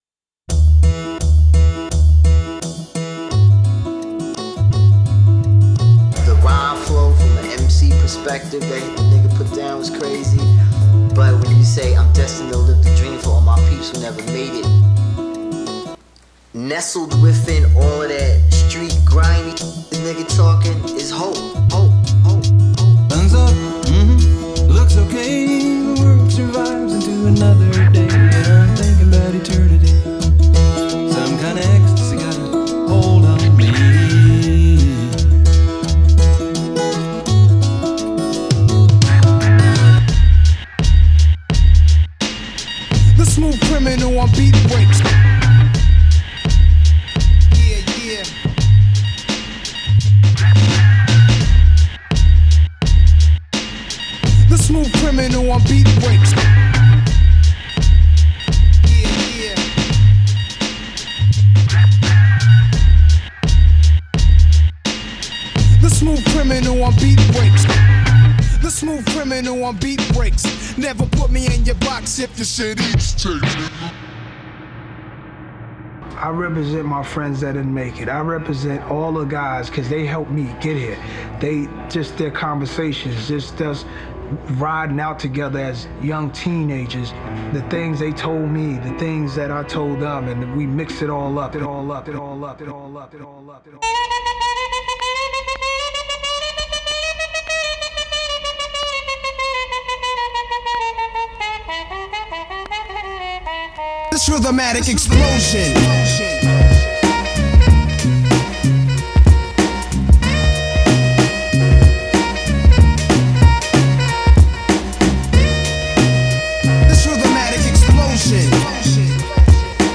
My Beat was #4:
I couldn’t quite get the bass to sound how I wanted on this first part - it is hard to find a way to program basslines from samples on the Digitakt - later on in the track I was happy with how I did this.